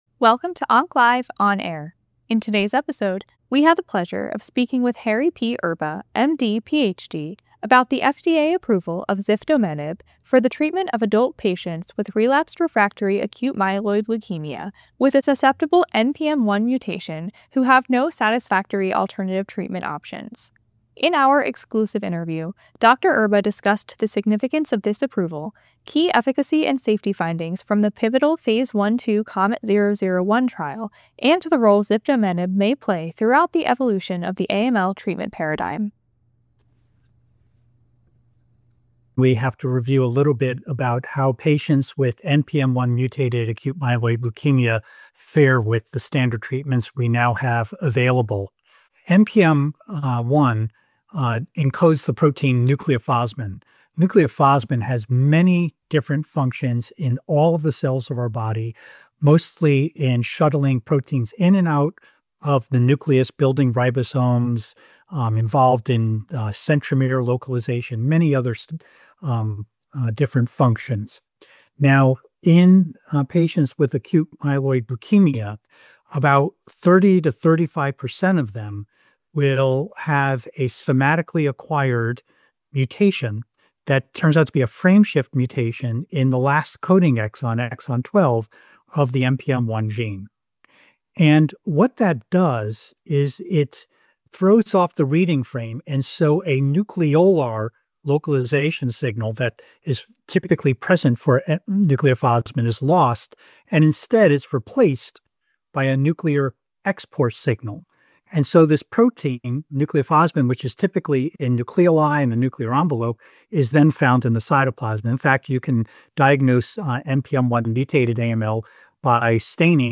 Check back throughout the week for exclusive interviews with leading experts in the oncology field.